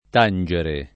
t#nJere] v.; tango [t#jgo], ‑gi — pass. rem. (raro) tansi [t#nSi]: Amore non mi tanse e non mi tange [am1re nom mi t#nSe e nnom mi t#nJe] (Gozzano) — non in uso il part. pass. e i tempi composti